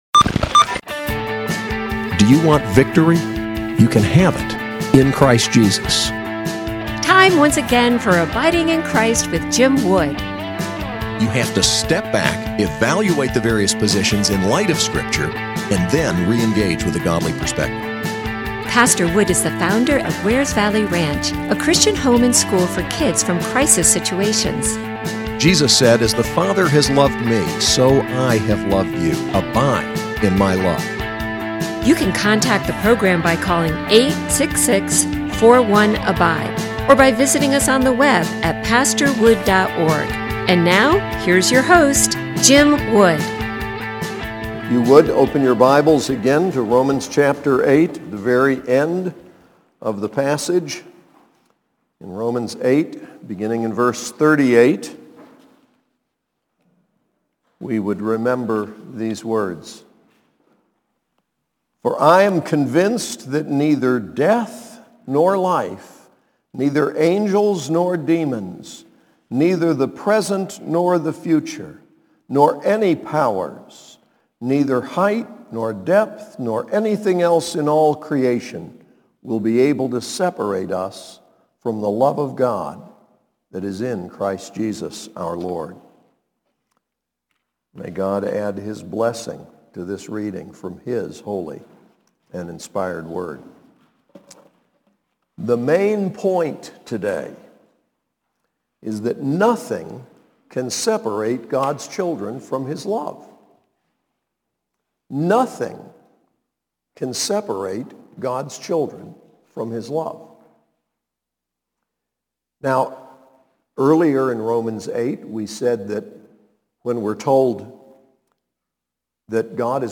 SAS Chapel: Victory in Christ, Part 3